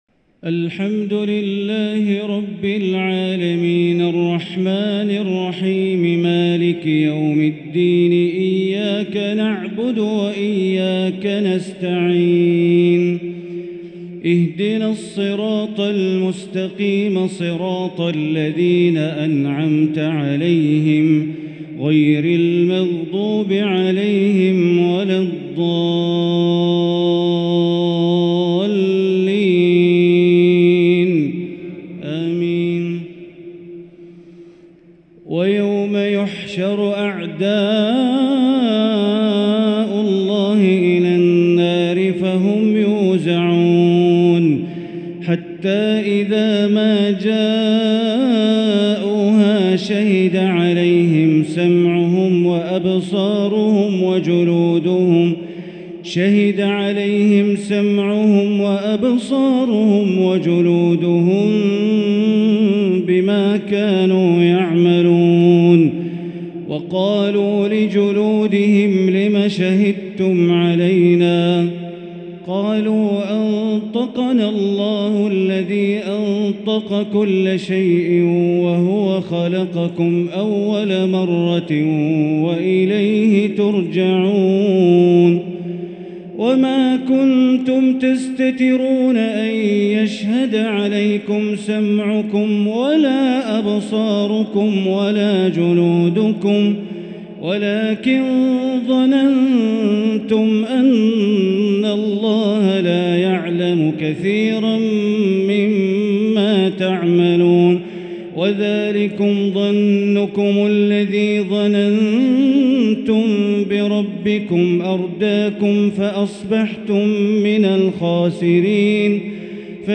تراويح ليلة 26 رمضان 1444هـ سورة فصلت (19-54) الشورى (1-16) |taraweeh 26st niqht Surah Ghafir_ and Al-Shura 1444H > تراويح الحرم المكي عام 1444 🕋 > التراويح - تلاوات الحرمين